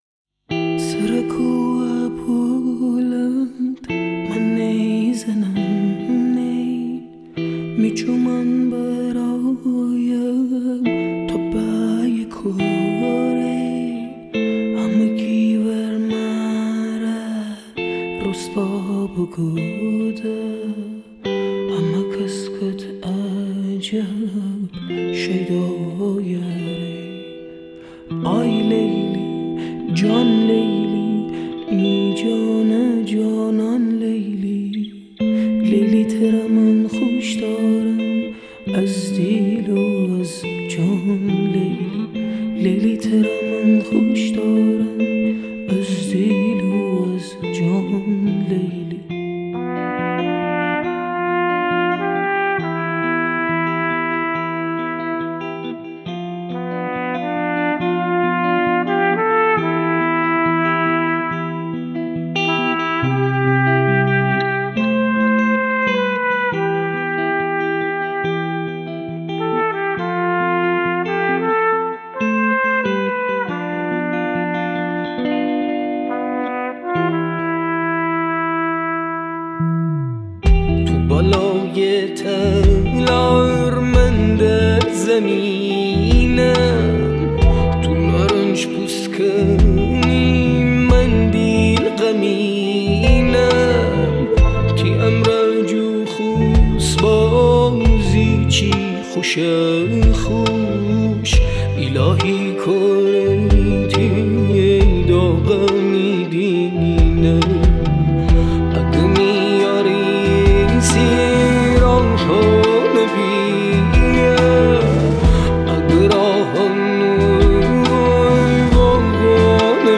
تک آهنگ